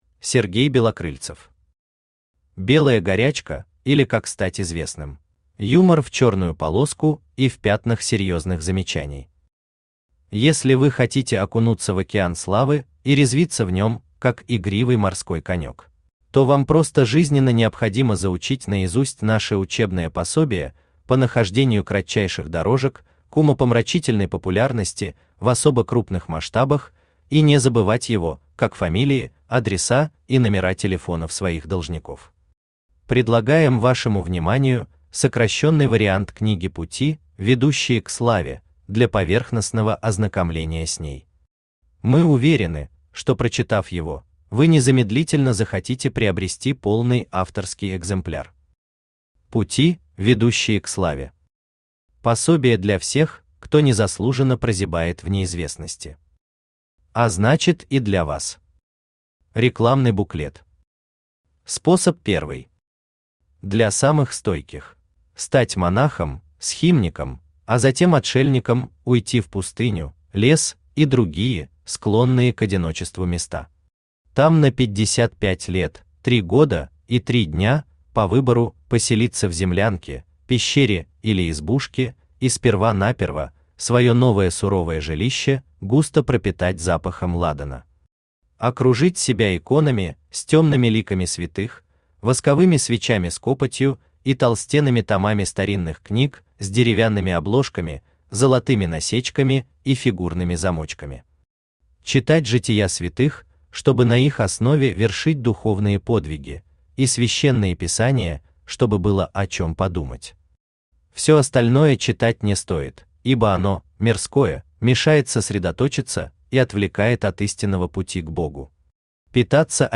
Aудиокнига Белая горячка, или Как стать известным Автор Сергей Валерьевич Белокрыльцев Читает аудиокнигу Авточтец ЛитРес.